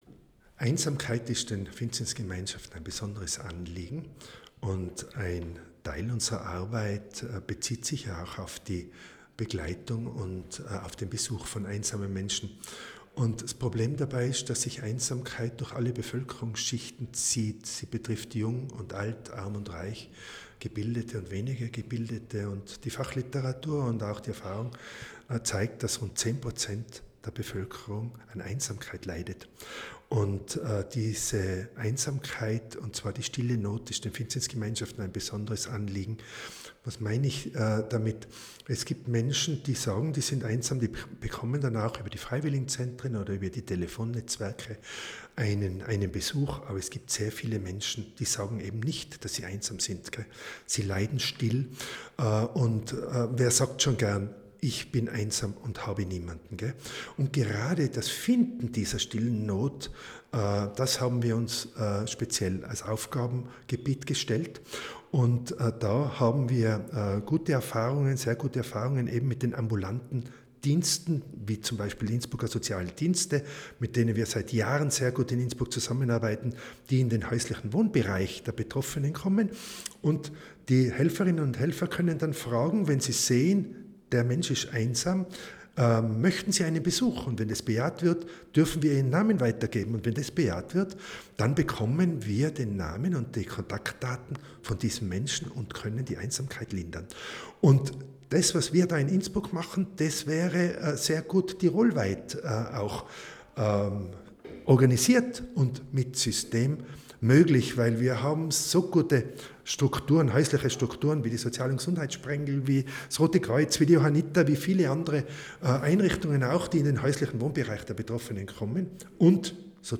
Pressegespräch: Einsamkeit aufspüren - Die stille Not lindern
O-Ton